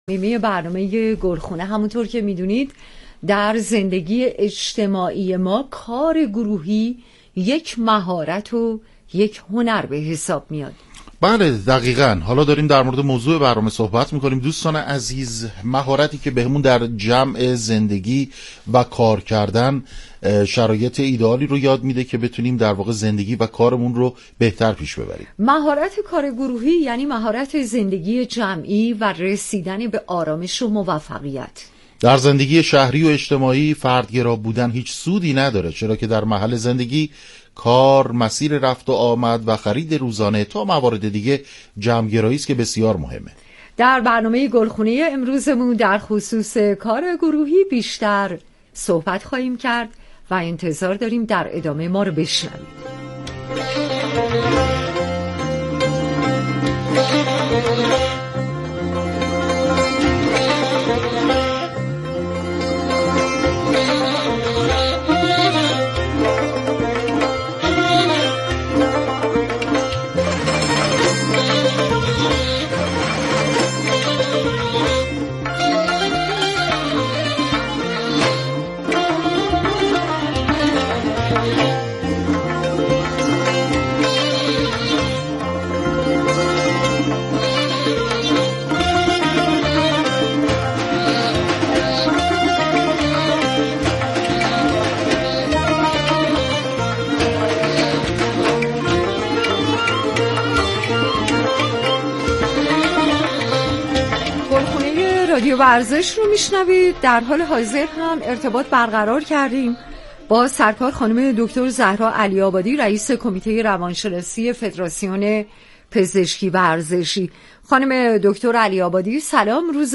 گفتگوی رادیویی